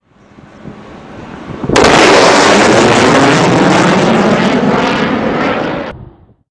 sonicboom.wav